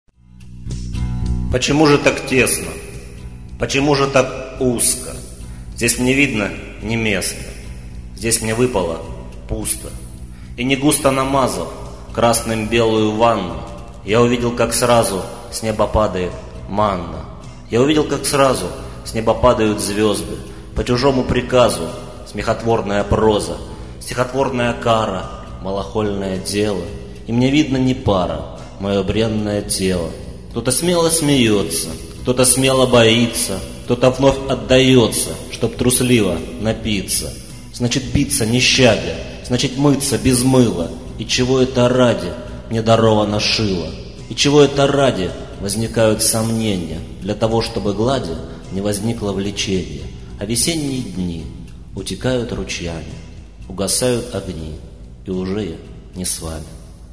стих